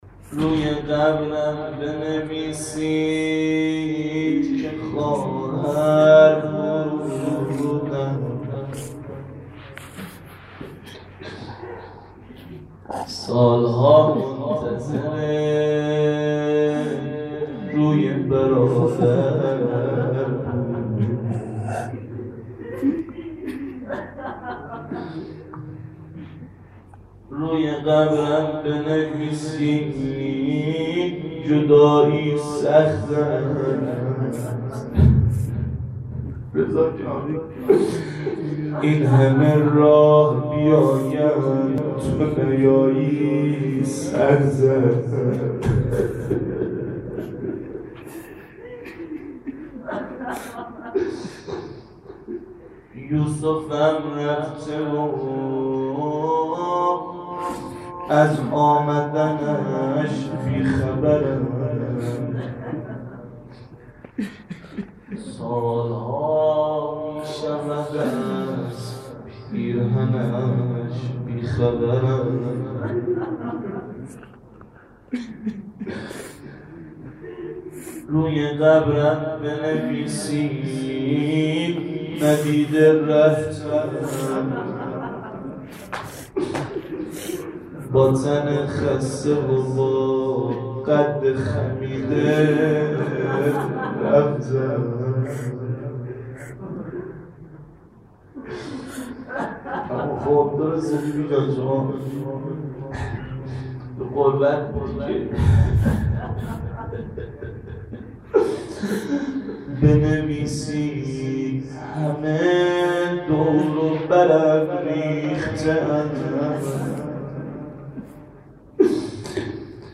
roze-rehlate-hazrate-masume.mp3